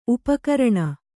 ♪ upakaraṇa